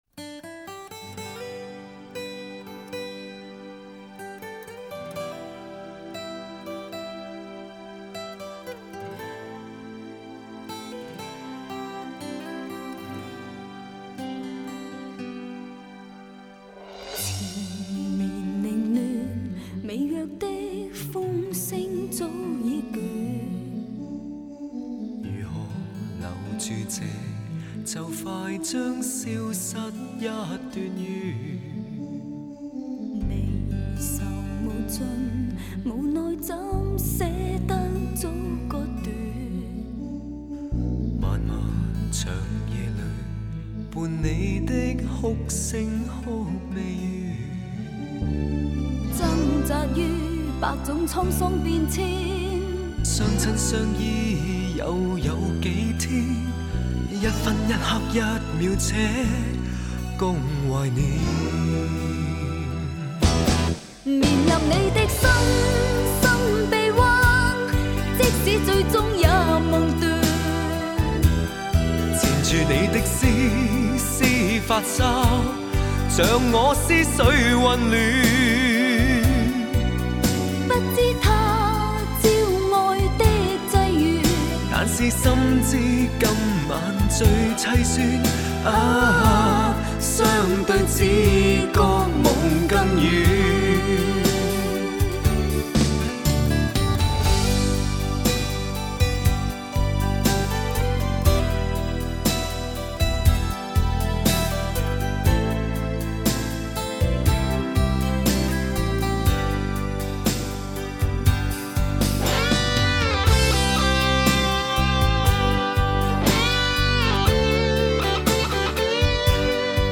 Ps：在线试听为压缩音质节选，体验无损音质请下载完整版 女∶缠绵凌乱，微弱的风声早已倦。